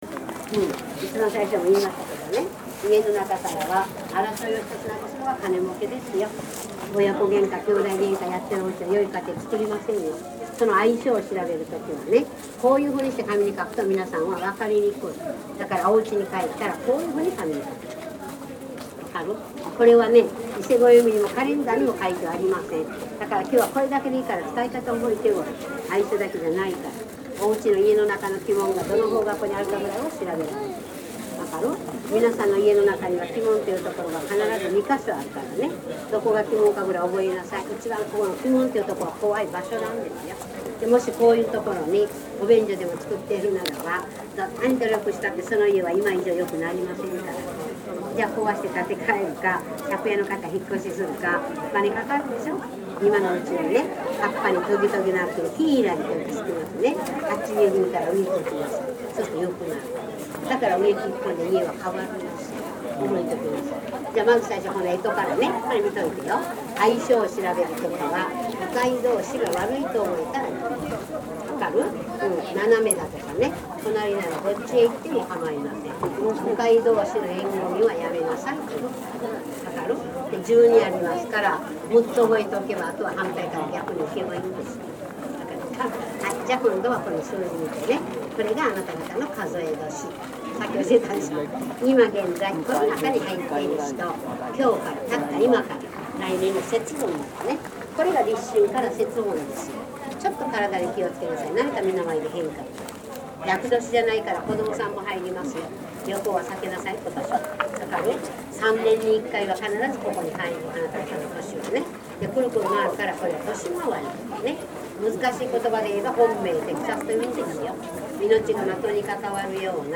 藤祭辻説法.mp3